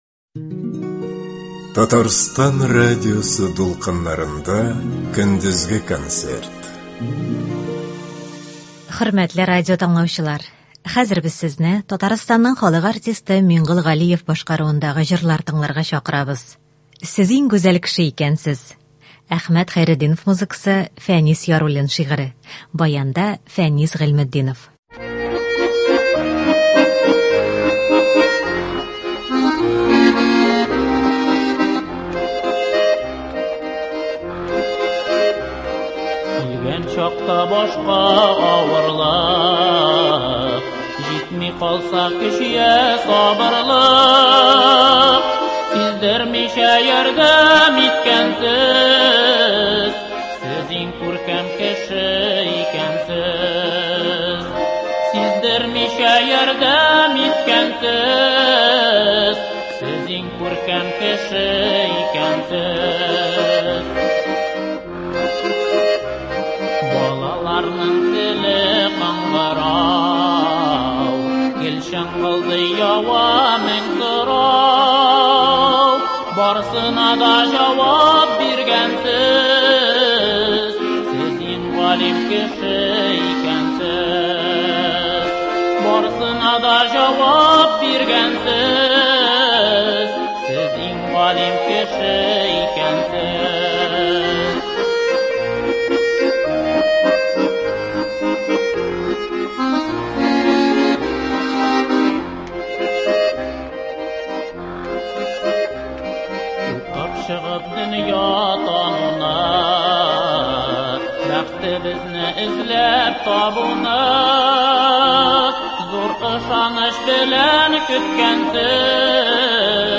Көндезге эфирны таныш моңнар белән бизәп җибәрик